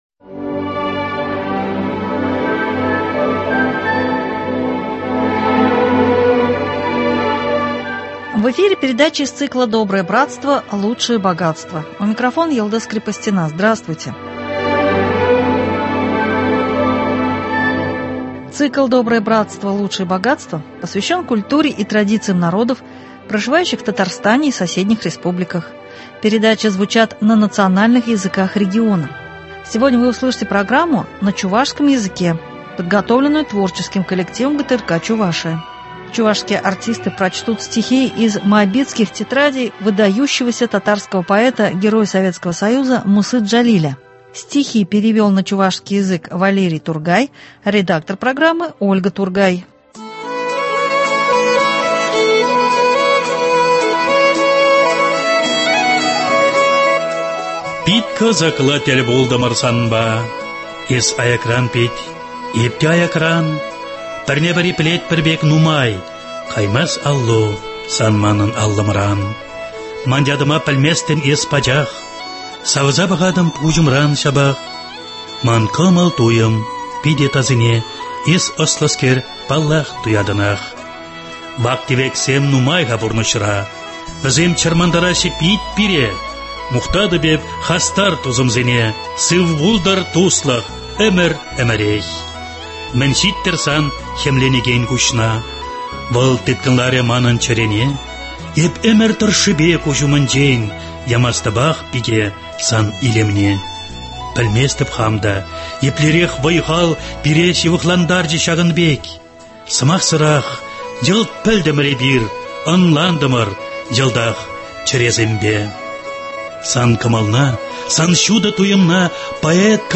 Чувашские артисты прочтут стихи из Моабитских тетрадей выдающегося татарского поэта, героя Советского Союза Мусы Джалиля.